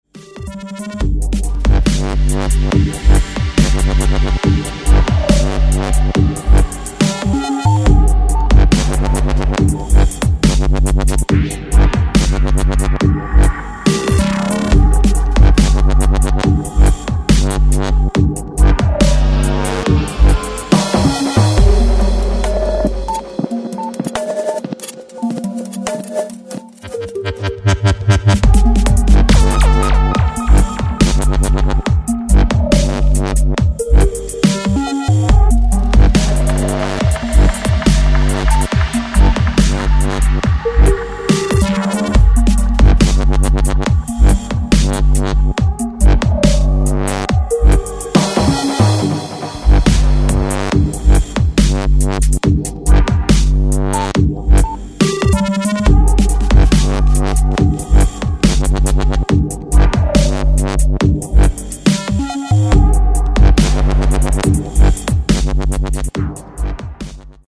Styl: Dub/Dubstep